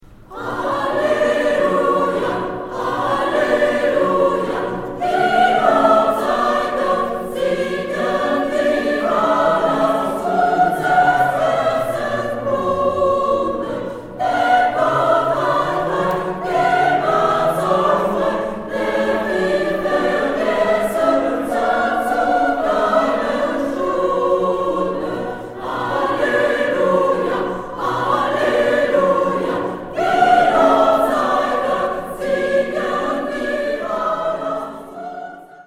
Chorale Vivavoce
Cantate BWV 110